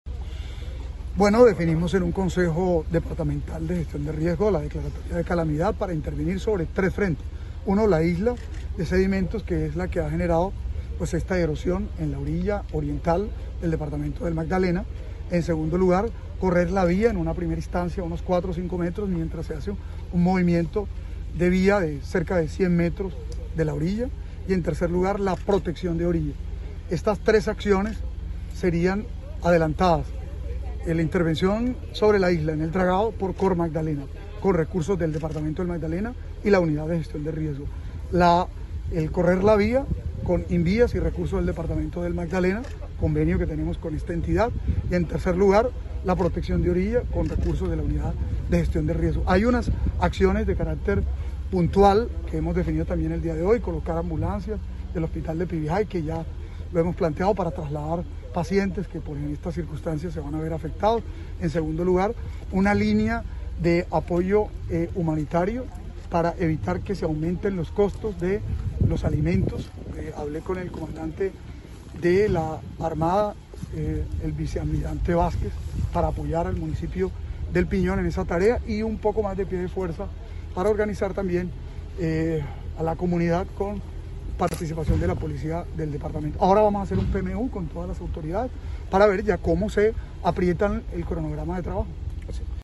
Gobernador-inicio-de-obras-en-vía-Salamina-El-Piñón-online-audio-converter.com_.mp3